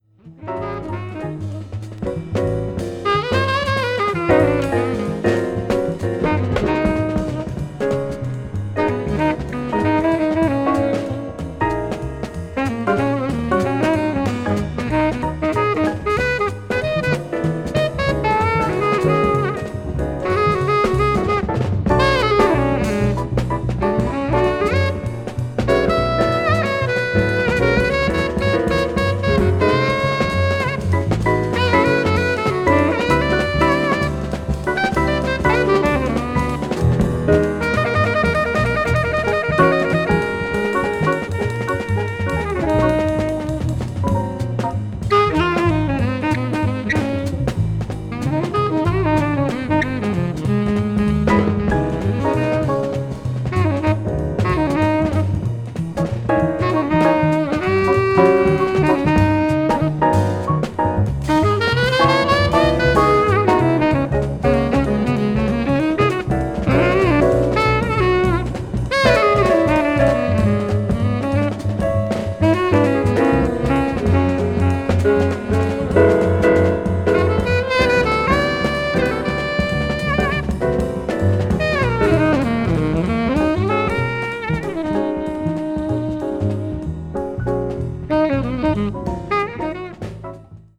EX(some lightly noises. side A has some light noises.
represents West Coast cool jazz.
piano
bass
drums